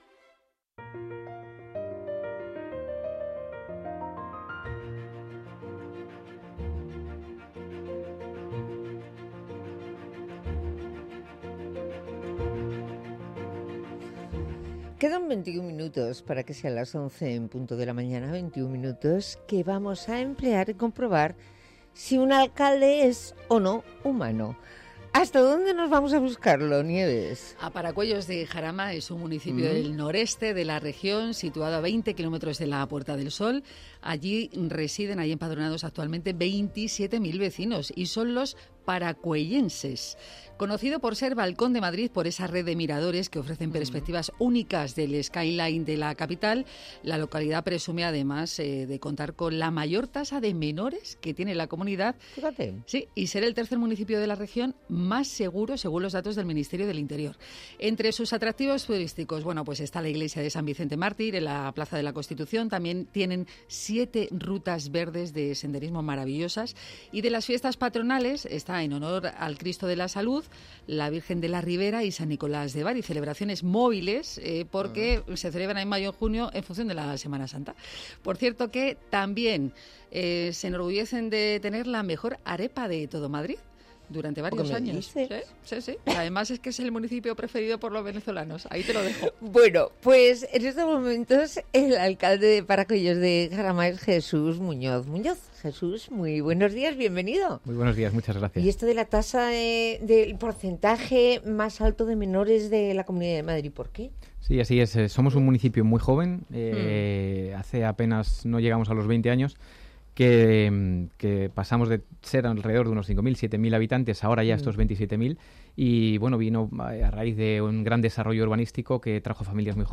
El regidor de Paracuellos de Jarama ha sido el invitado de la sección Los alcaldes también son humanos del programa Buenos Días Madrid.